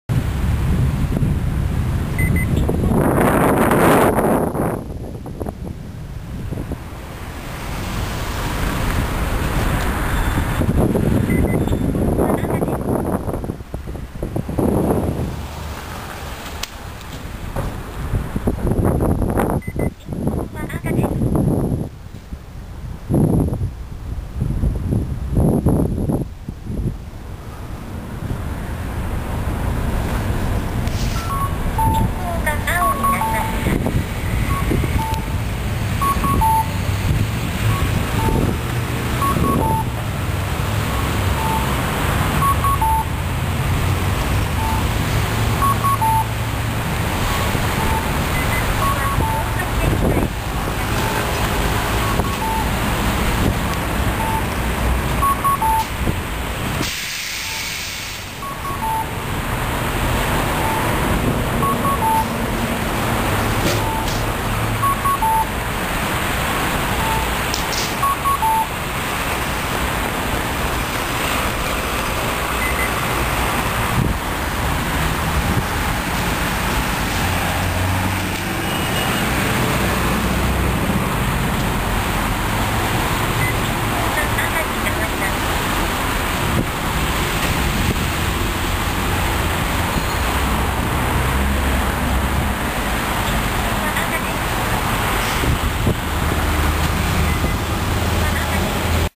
ようやく交差点名をしゃべる歩行者支援装置を見つけました。
４９秒付近でうまく交差点名を言わせることに成功しました。
収録当時、随分と風が強かったようですね(**;)。